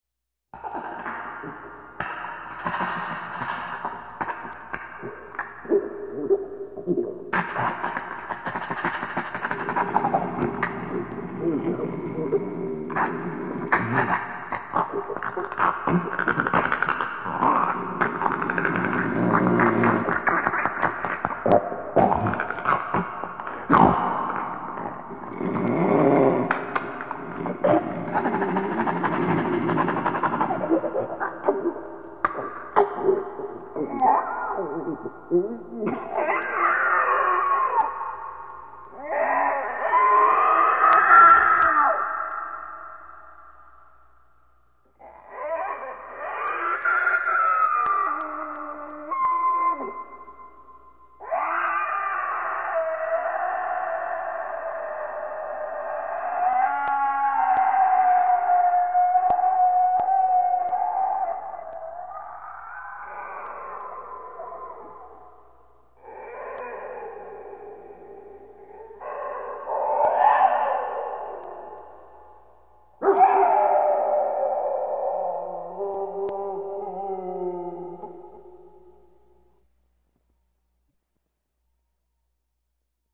Suonerie Rumori